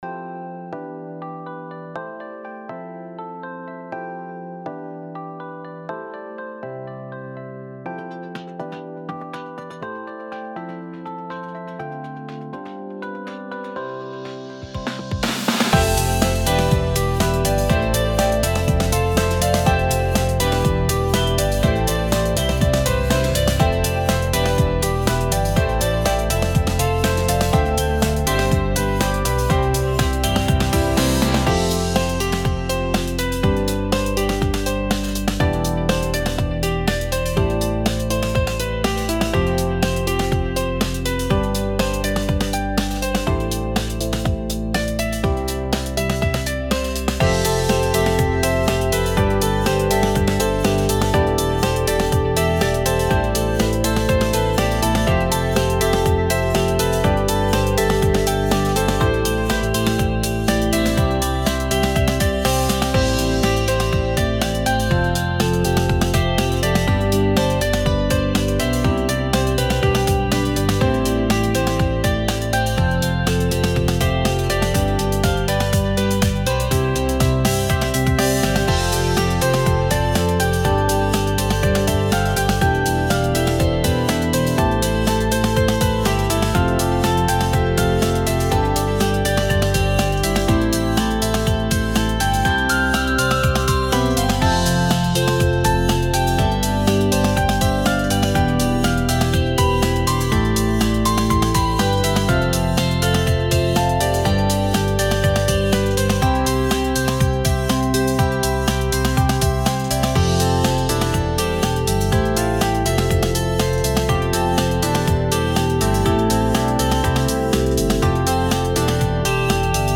「日常」のテーマに相応しい音楽です。